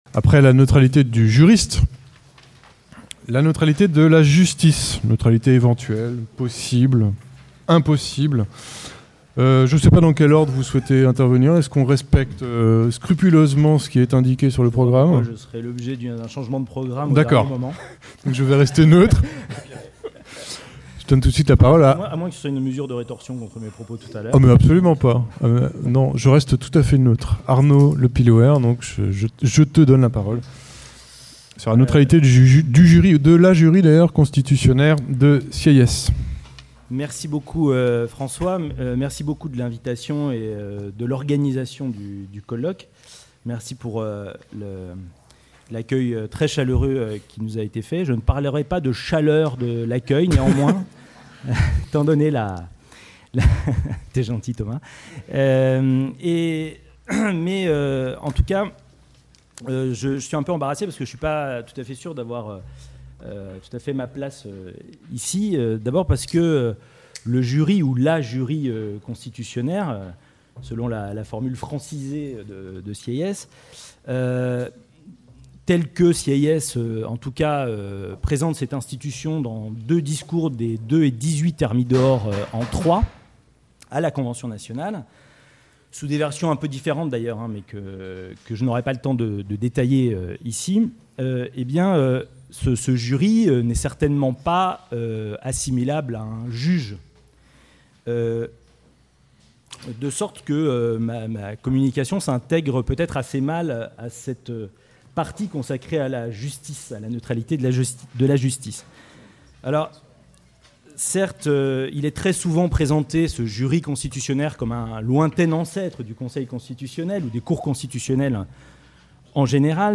Retour sur le colloque : La neutralité (13 et 14 octobre 2016) Colloque de la promotion 2014 de l'agrégation de droit public Le colloque des 13 et 14 octobre 2016, réunissant les lauréats et membres du jury du concours d’agrégation de droit public 2014, a été l’occasion de mettre en perspective avec succès la neutralité en droit, en interrogeant ses raisons d’être et ses formes, mais en insistant aussi sur ses limites voire ses impasses.